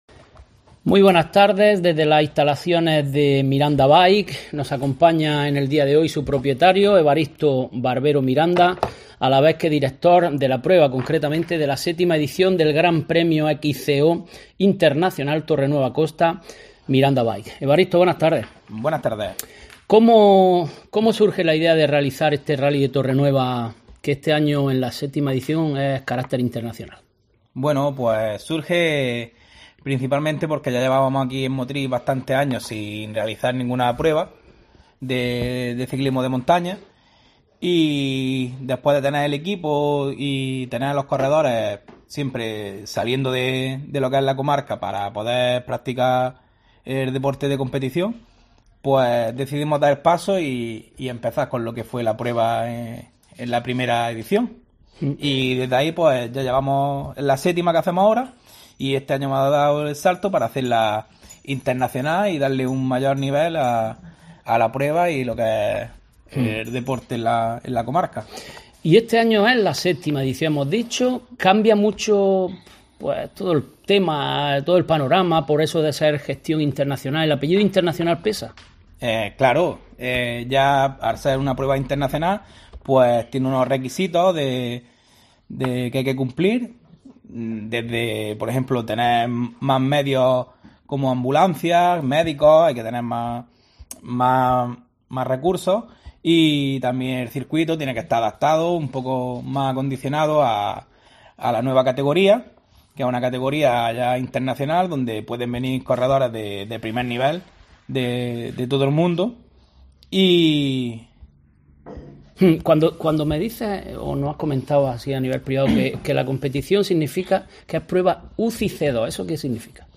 Hablamos con